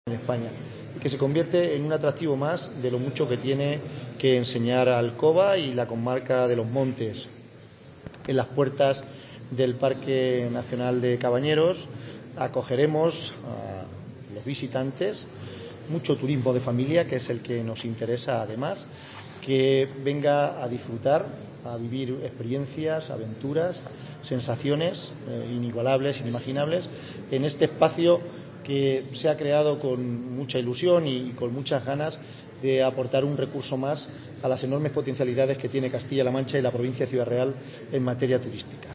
José Manuel Caballero, presidente Diputación Ciudad Real